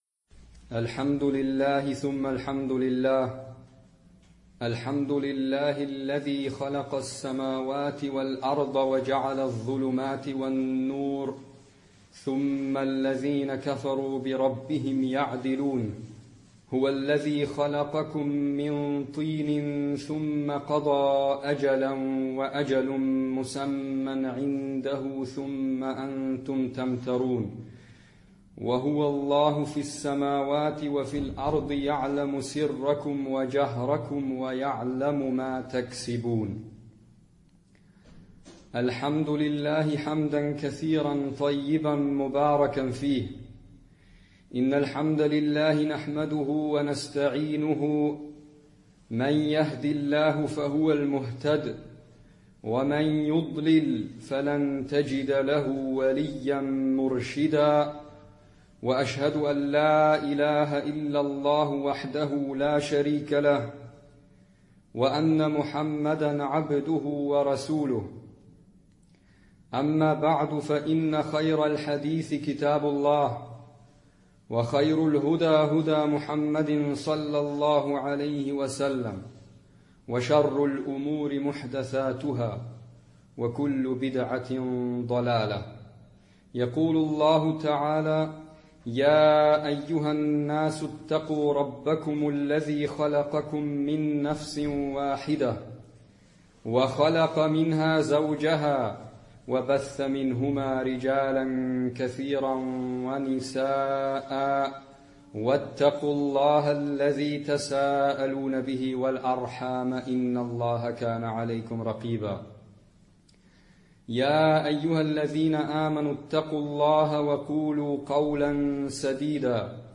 Die eigentliche Ansprache besteht aus zwei Teilen, dazwischen eine kurze Pause. Am Ende folgt das Gebet mit Rezitation von Versen aus dem Koran, welche meistens auch einen Bezug zum Thema haben oder in der Ansprache erwähnt werden. Die im deutschen Hauptteil der Ansprache zitierten arabischen Quelltexte unterbrechen den Redefluss nicht nennenswert und werden immer übersetzt.
Ursprünglicher Ort der Ansprache: al-Iman-Moschee in Wien